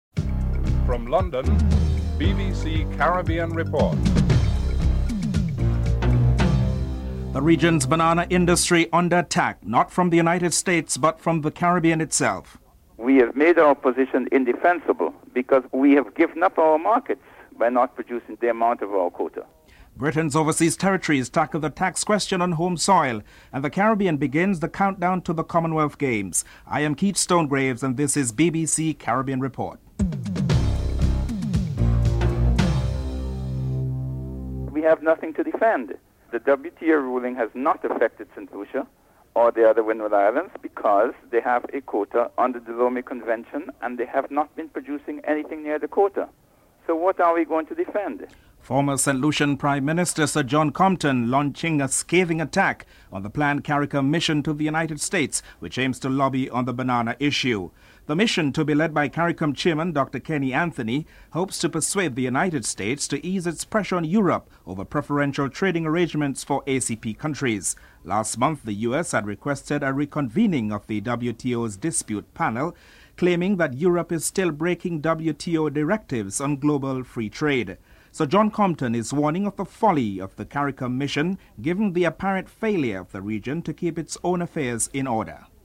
1. Headlines (00:00-00:31)
Prime Minister Keith Mitchell is interviewed (04:22-07:34)